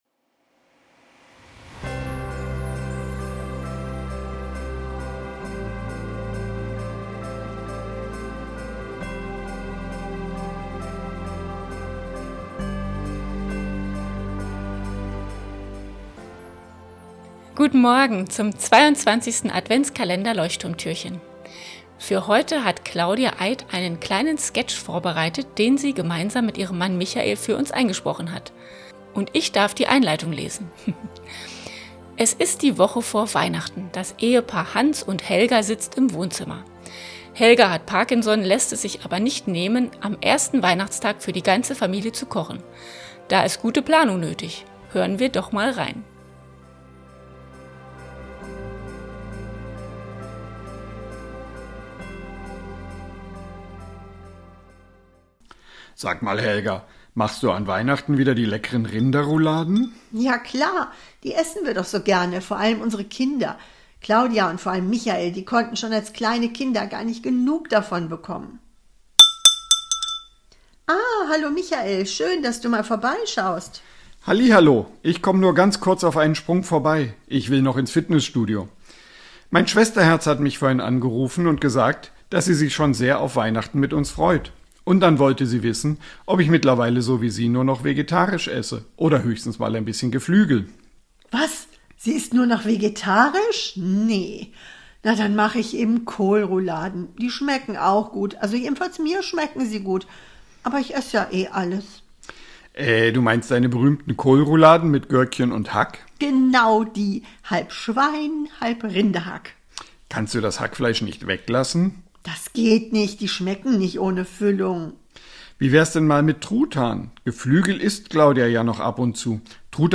Sketch